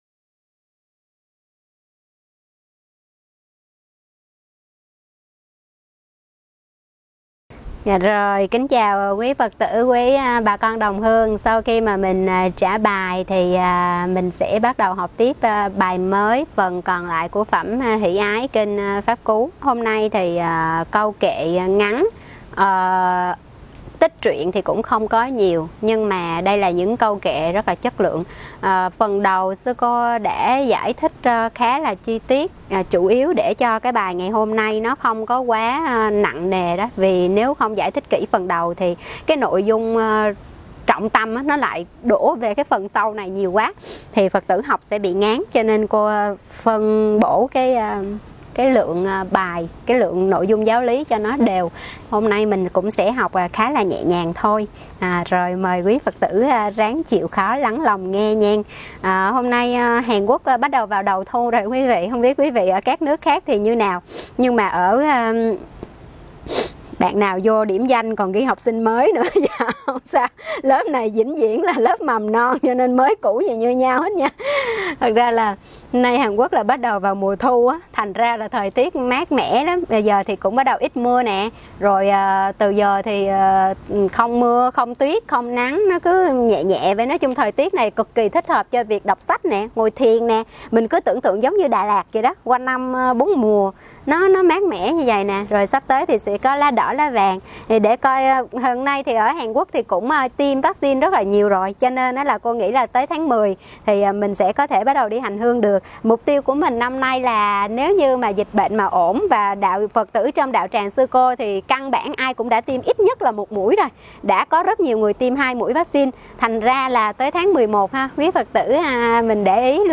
Nghe mp3 thuyết pháp Để hết khổ được an